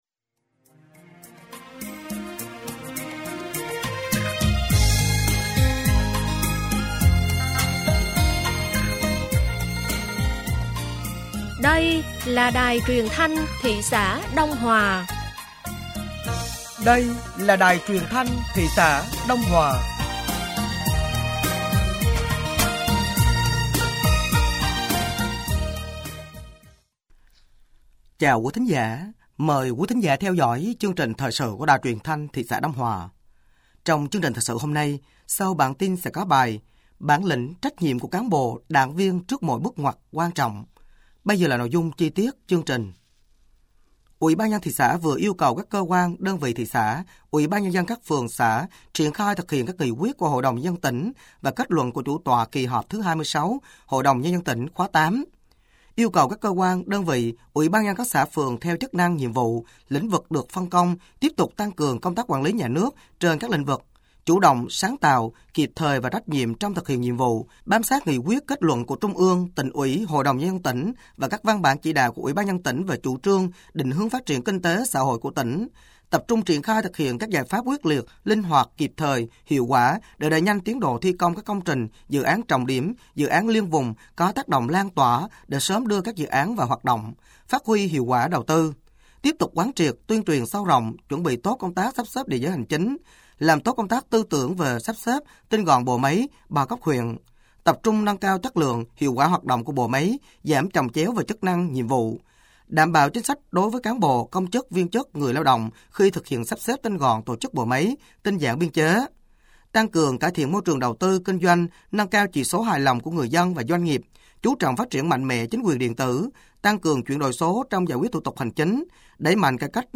Thời sự tối ngày 13 và sáng ngày 14 tháng 4 năm 2025